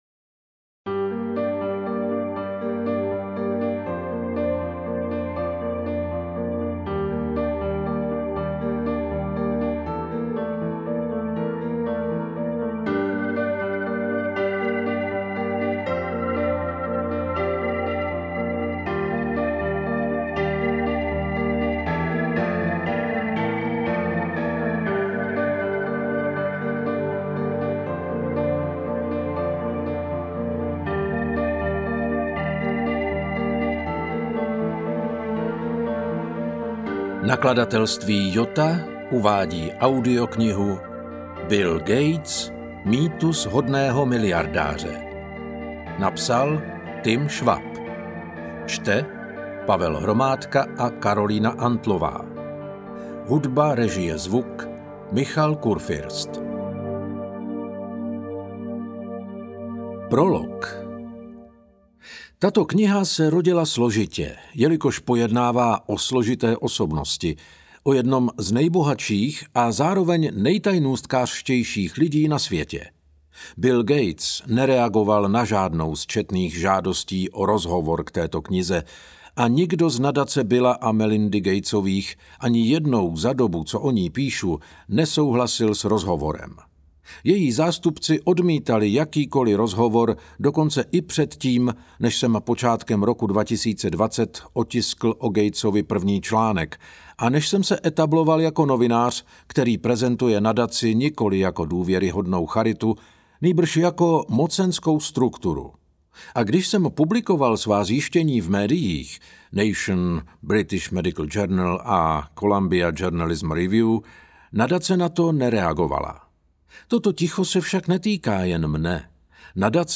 Audio knihaBill Gates
Ukázka z knihy